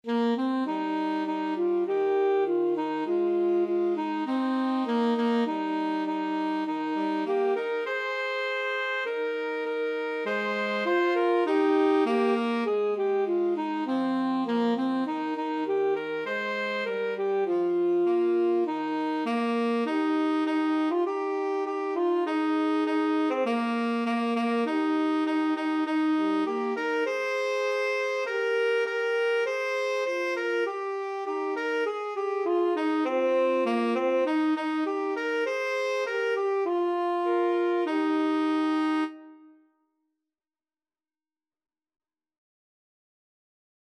Alto SaxophoneTenor Saxophone
4/4 (View more 4/4 Music)